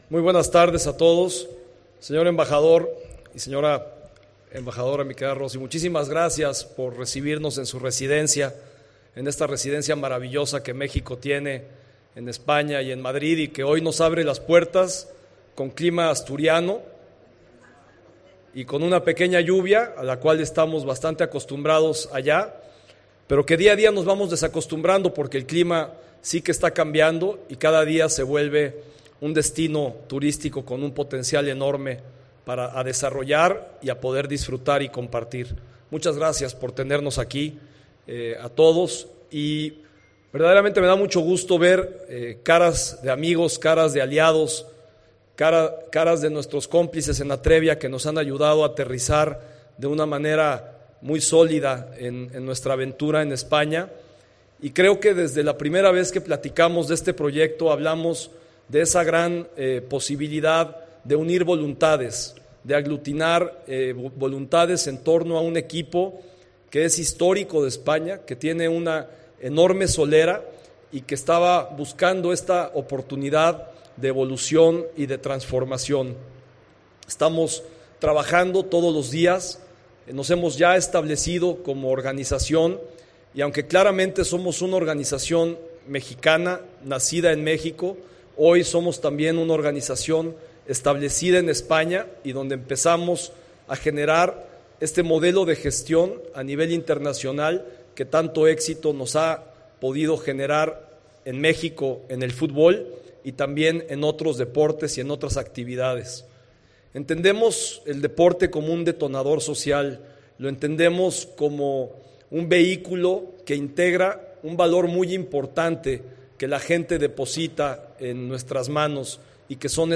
Discurso
en la Embajada de México en España